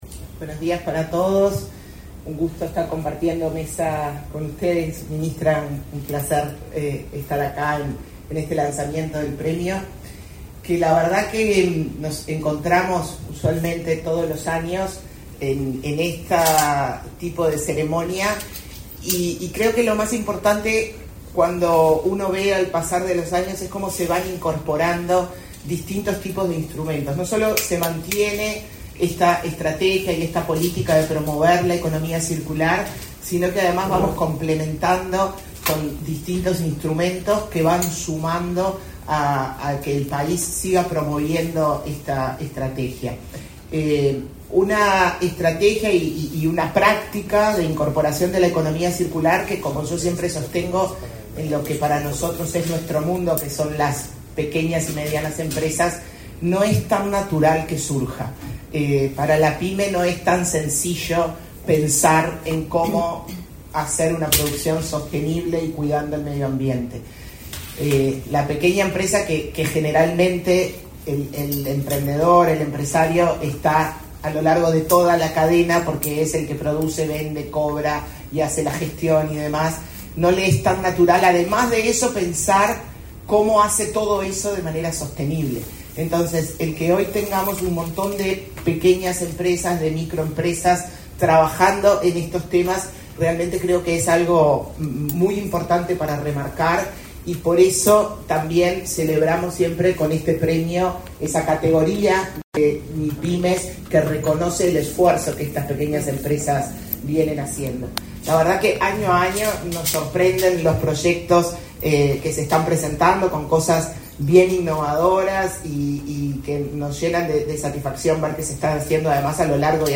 Acto por el lanzamiento de la edición 2024 del Premio Uruguay Circular
El Ministerio de Industria, Energía y Minería lanzó, este 20 de junio, la edición 2024 del Premio Uruguay Circular, que reconoce y visibiliza los proyectos que promueven la economía circular en el país. Participaron en el evento, la titular de la citada cartera, Elisa Facio; la presidenta de la Agencia Nacional de Desarrollo, Carmen Sánchez, y el presidente de la Agencia Nacional de Investigación e Innovación, Flavio Caiafa.